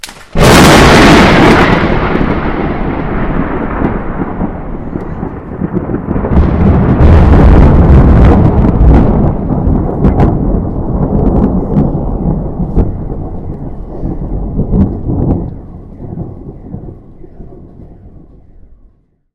Оглушительный звук грома